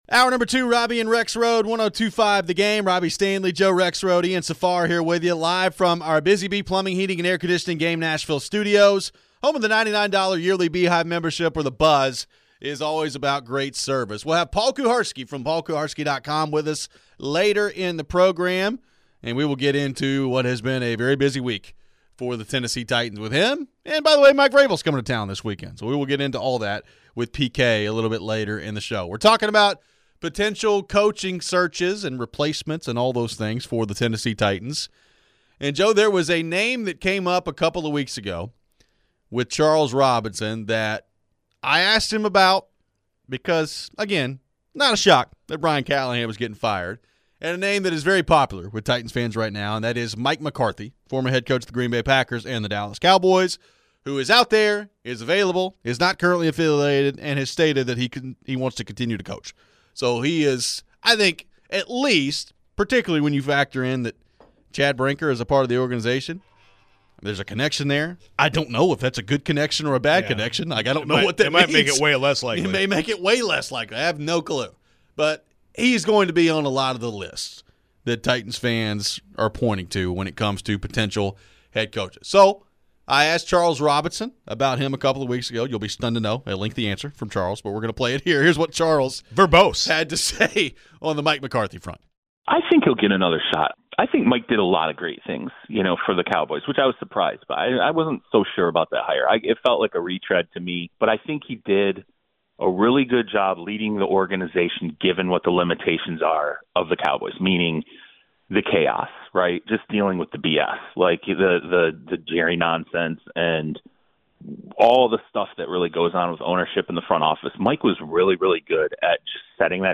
Do we think he could be a fit for the Titans? We get back to your phones on the Titans and the potential coach.